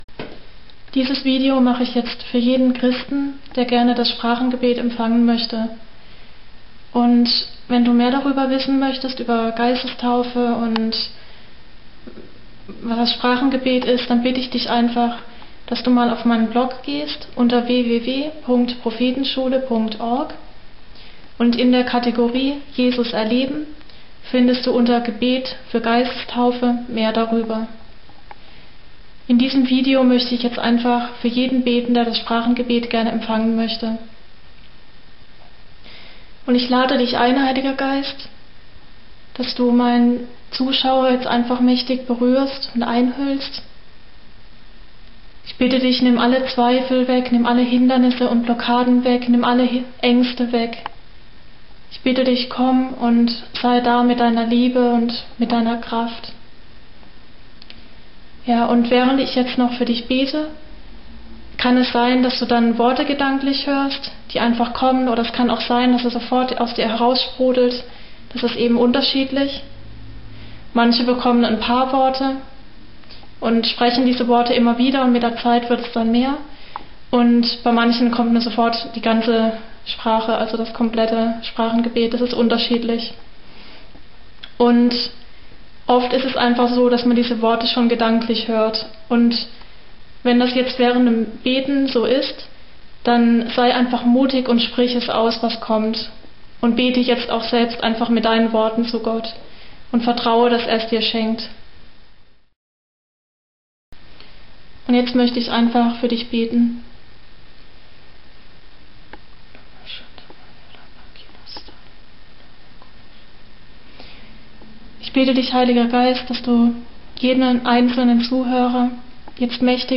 gebet-fc3bcr-geistestaufe.mp3